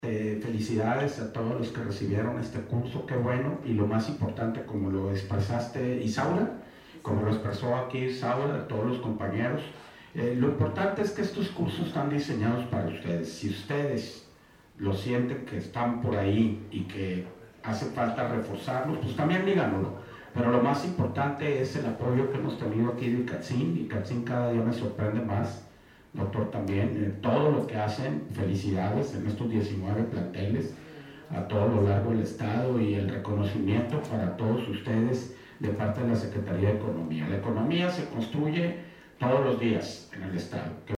En esta entrega a 16 artesanos graduados de dicho diplomado, el Secretario de Economía, Javier Lizárraga Mercado, dijo que la economía de Sinaloa la construyen mujeres y hombres, todos en una sola dirección, para mejorar las condiciones de vida de las familias.